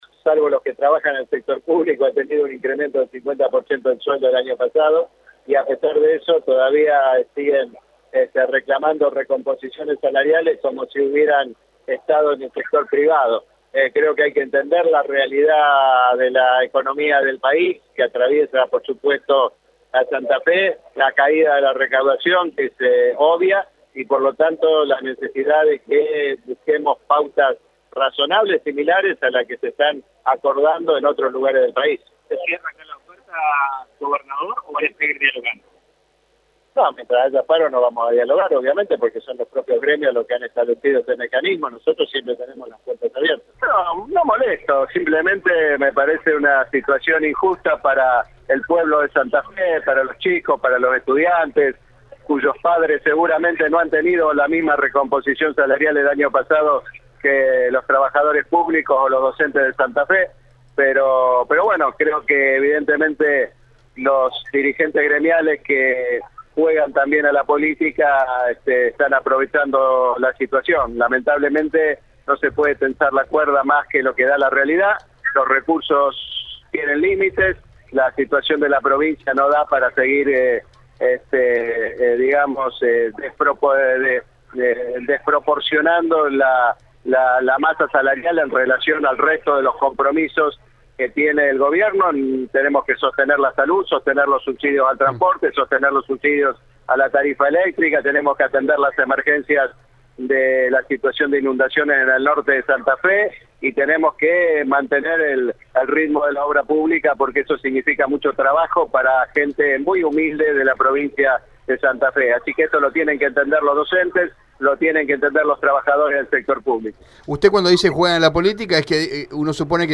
A través del micrófono de Radio EME, Lifschitz pronunció con energía que «salvo los que trabajan en el sector público han tenido un incremento del 50% del sueldo el año pasado y a pesar de eso todavía siguen reclamando recomposiciones salariales como si hubieran estado en el sector privado».